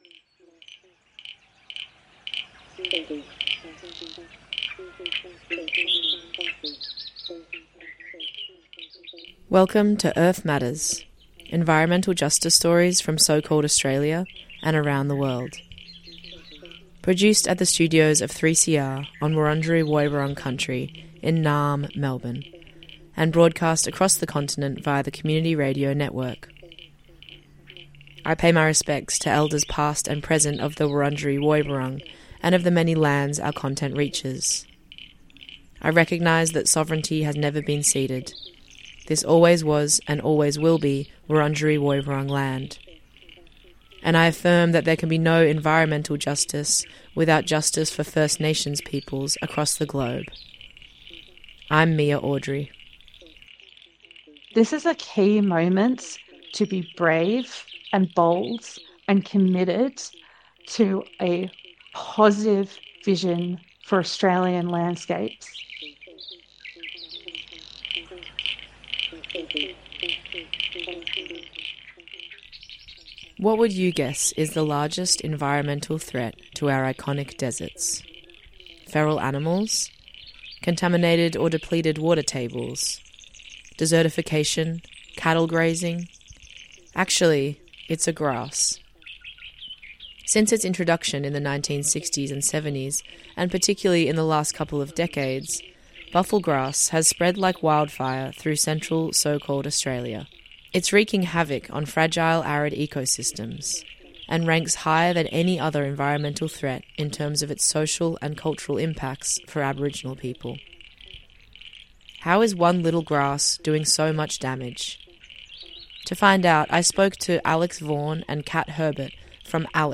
Today on Earth Matters environmentalists and activists tell us about the impact the proposed seismic blasting will have on the Otway Basin, what they are doing to stop it and problems with the consultation processes of companies planing to conduct seismic blasting in the Otway Basin and their representatives.